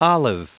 Prononciation du mot olive en anglais (fichier audio)
Prononciation du mot : olive